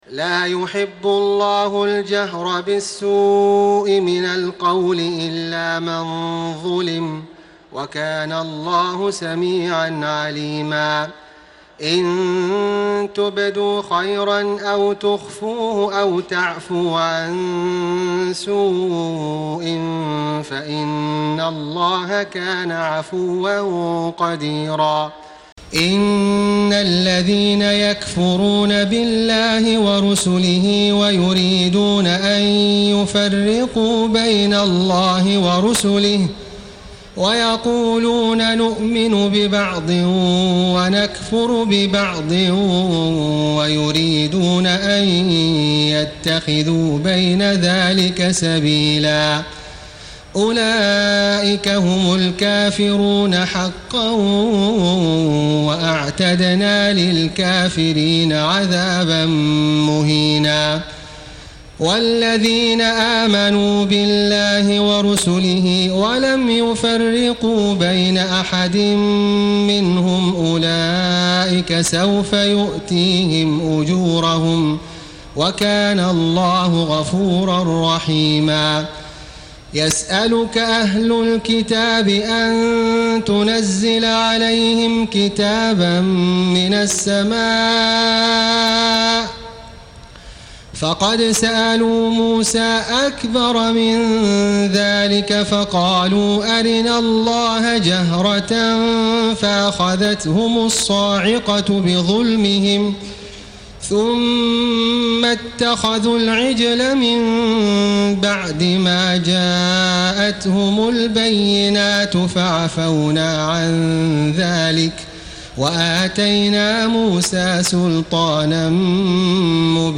تهجد ليلة 26 رمضان 1434هـ من سورتي النساء (148-176) و المائدة (1-40) Tahajjud 26 st night Ramadan 1434H from Surah An-Nisaa and AlMa'idah > تراويح الحرم المكي عام 1434 🕋 > التراويح - تلاوات الحرمين